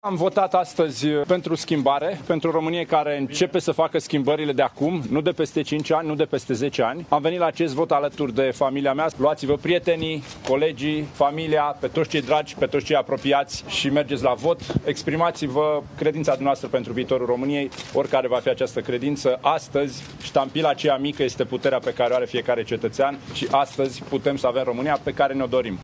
Candidatul USR Plus la alegerile prezidențiale, Dan Barna, a votat la Sibiu, iar când a introdus votul în urnă, a spus: „Aici e puterea cetățenilor”.